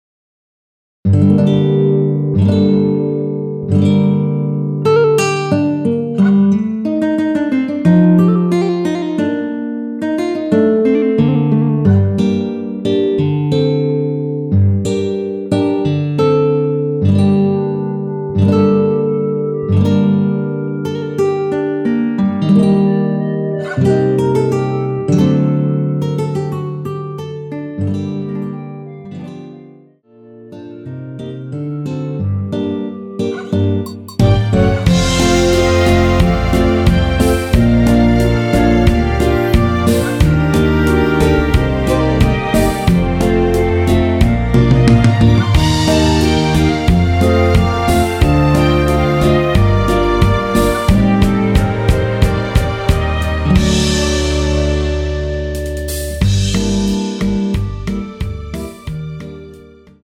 원키에서(-2)내린 멜로디 포함된 MR입니다.
Gm
앞부분30초, 뒷부분30초씩 편집해서 올려 드리고 있습니다.
(멜로디 MR)은 가이드 멜로디가 포함된 MR 입니다.